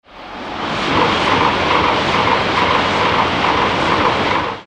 電車や車、動物の鳴き声など、38種類の、色々な音が楽しめます。
汽笛